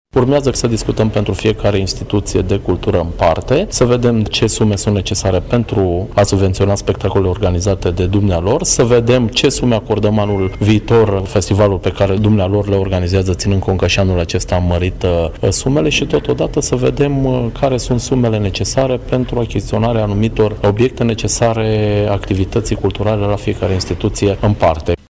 Mai mult, ar putea fi suplimentate și sumele pentru achiziţia de instrumente specifice instituţiilor, însă acest lucru va fi stabilit în urma discuţiilor cu managerii instituţiilor, după cum a precizat viceprimarul Mihai Costel: